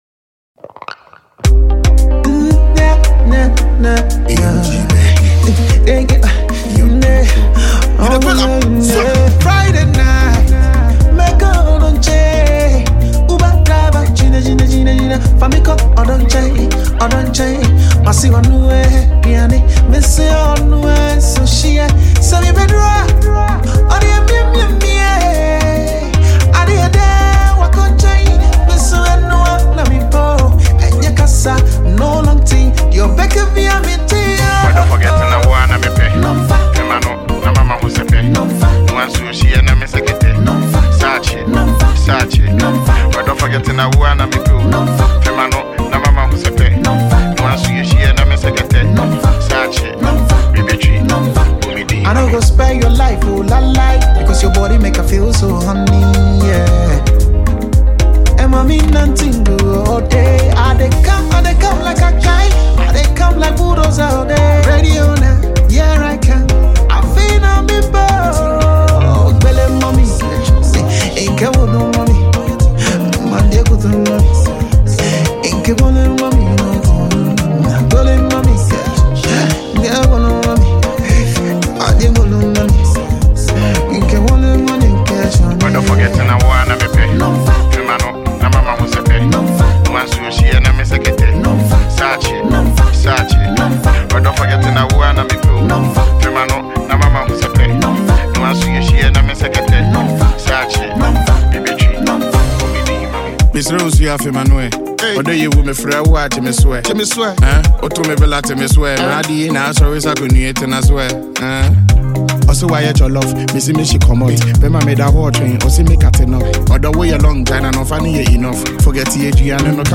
Ghana Music
Veteran Highlife singer
low tempo highlife record